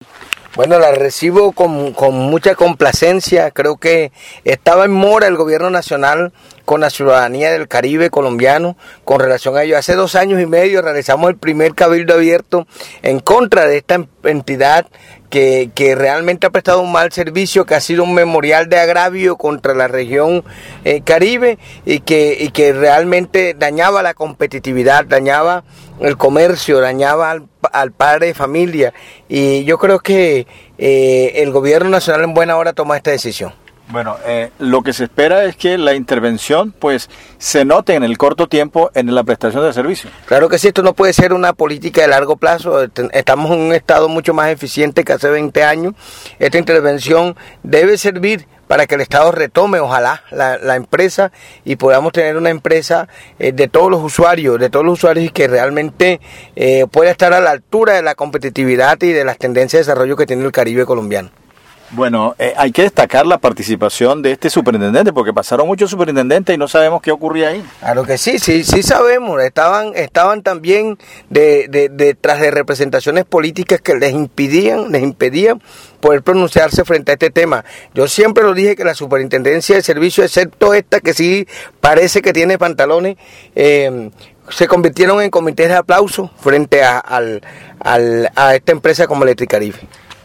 «Esperamos que esta intervención se refleje de manera pronta en el mejoramiento de la calidad del servicio», reiteró Ospino en diálogo con Atlántico en Noticias.